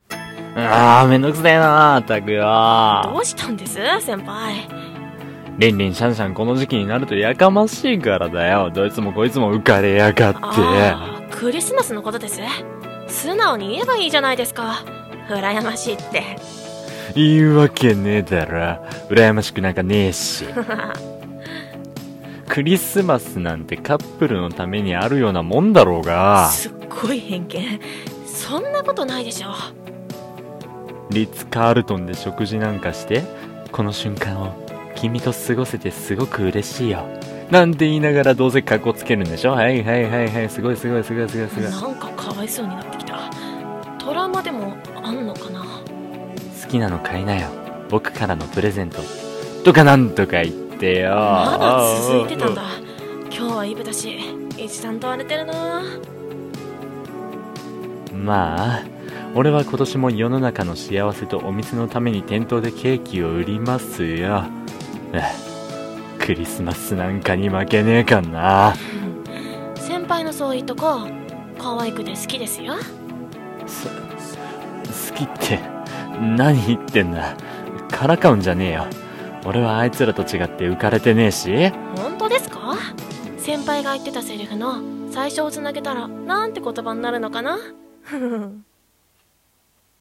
【二人声劇】クリスマス・イブ